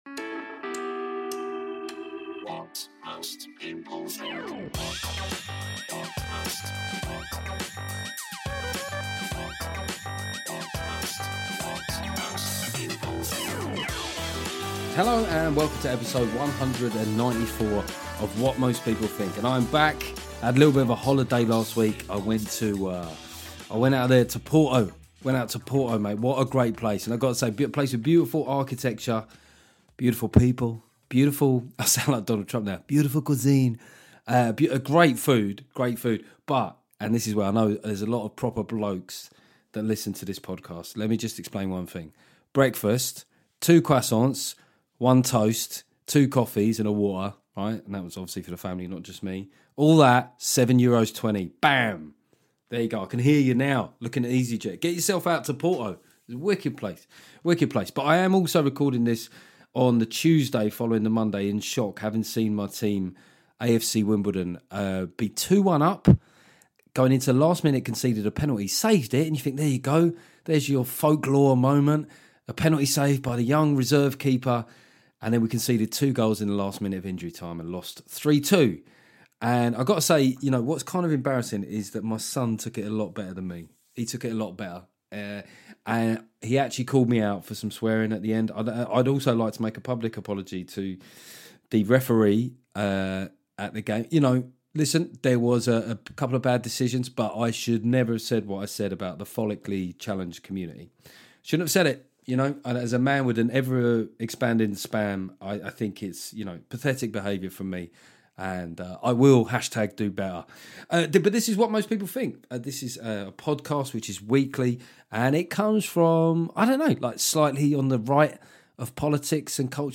Then it's a very interesting chat with Swedish comedian